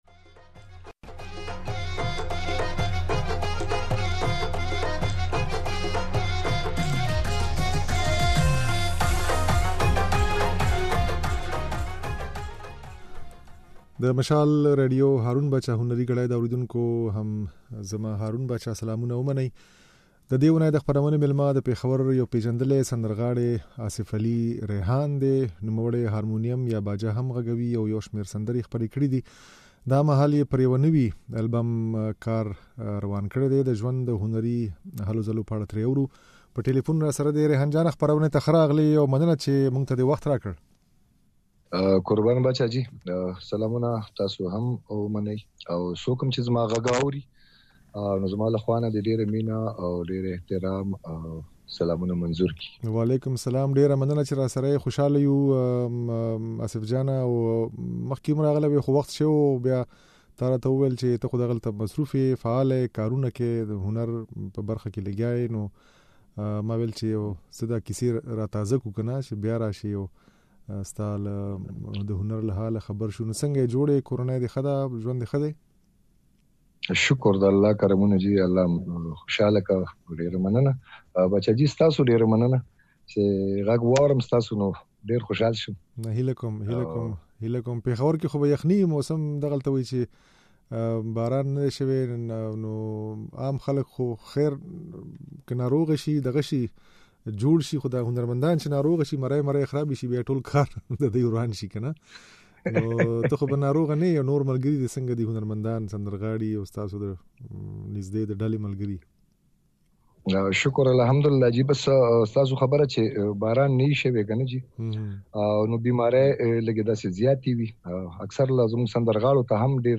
هارمونيم غږوونکی او سندرغاړی